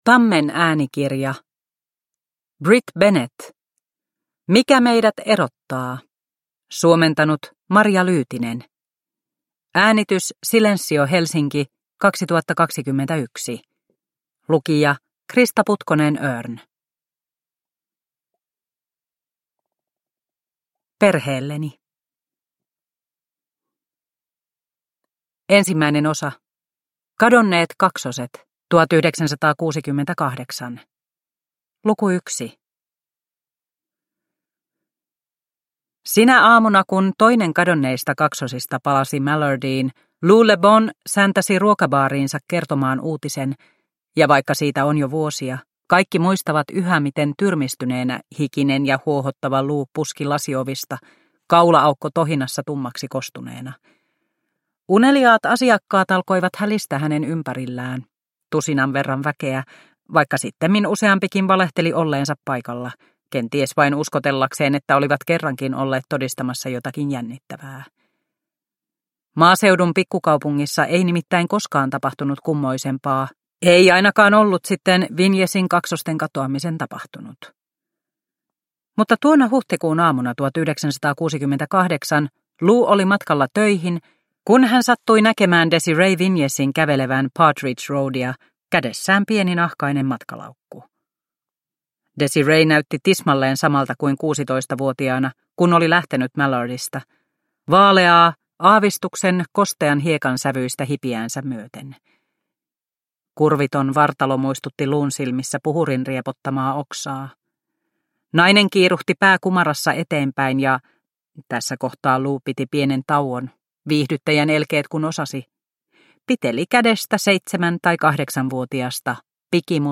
Mikä meidät erottaa – Ljudbok – Laddas ner